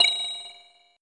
Collect_Sound.wav